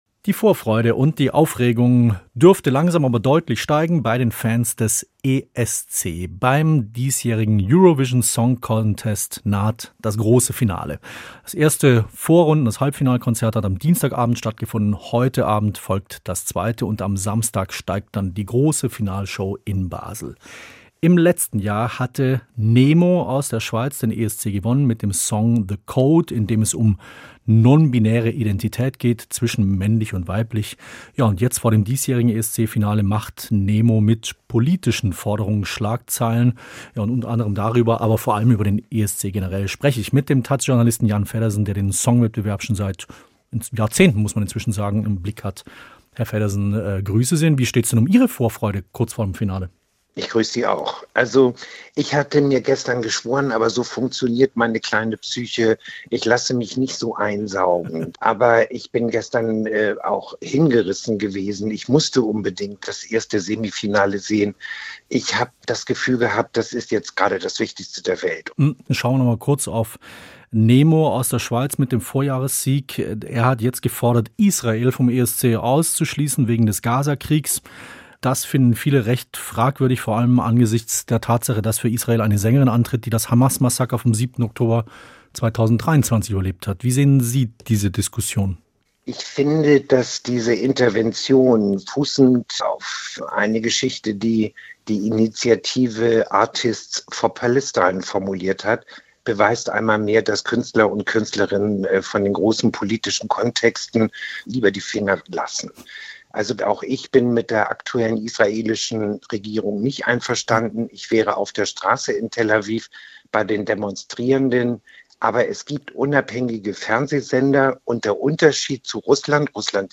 auch wenn er im Gespräch mit SWR Kultur erzählt